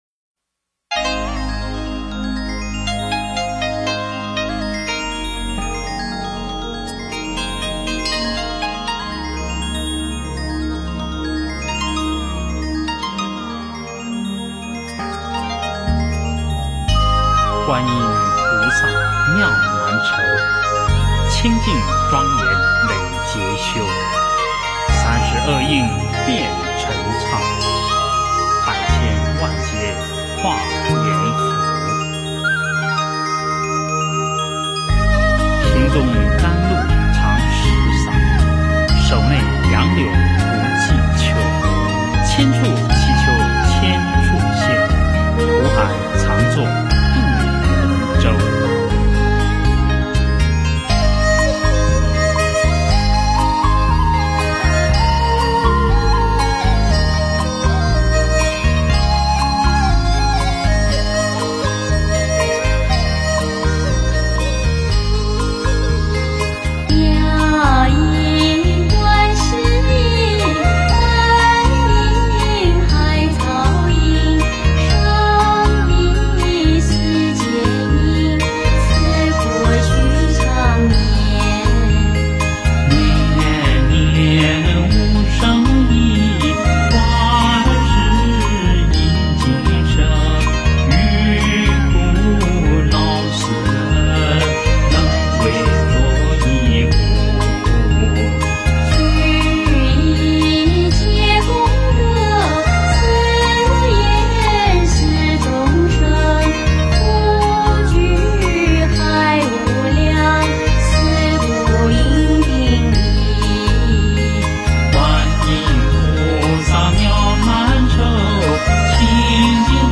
佛音 诵经 佛教音乐 返回列表 上一篇： 佛法在世间 下一篇： 寒山钟声 相关文章 因果--佛教音乐(世界禅风篇